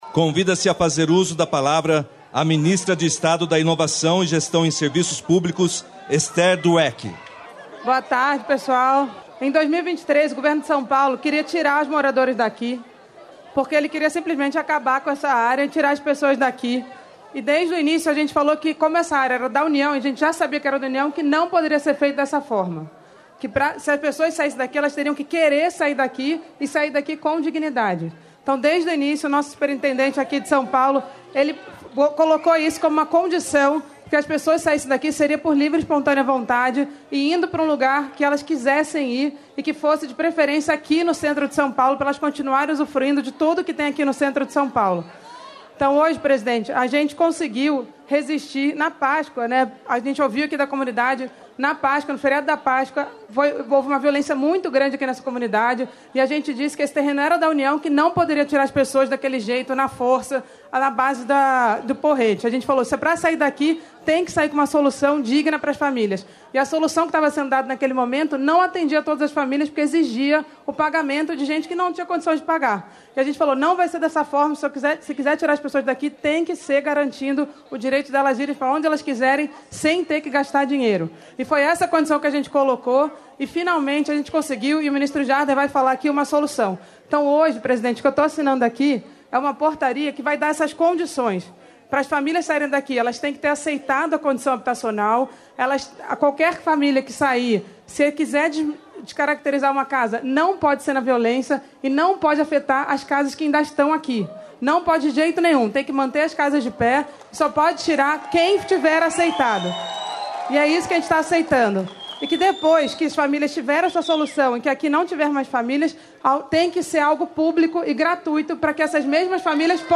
Íntegra dos discursos da ministra da Gestão e Inovação em Serviços Públicos, Esther Dweck, do presidente da Caixa, Carlos Vieira, e do ministro das Cidades, Jader Filho, na Assinatura de Atos Relativos à Solução Habitacional para a Favela do Moinho, em São Paulo, nesta quinta-feira (26).